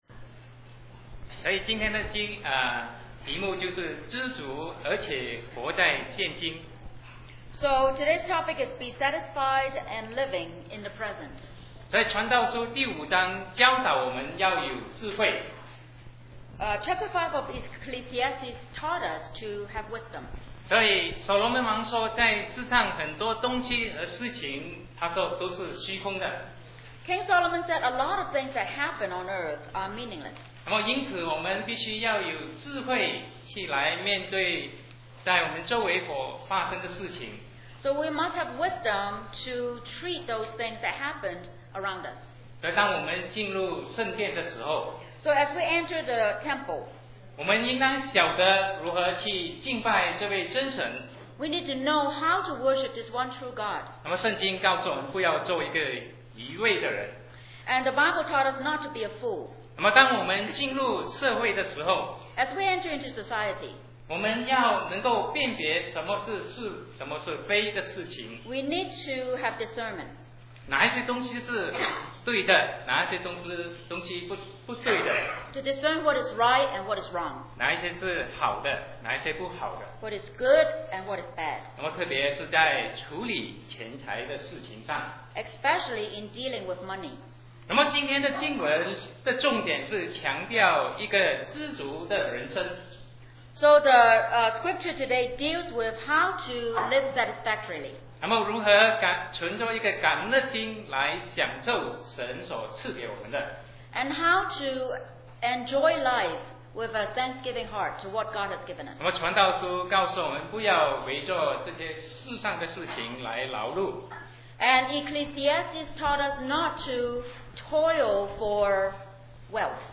Sermon 2007-11-11 Be Satisfied and Living in the Present